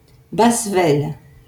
Bassevelle (French pronunciation: [basvɛl]